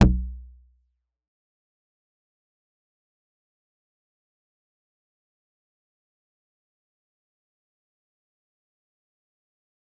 G_Kalimba-E1-f.wav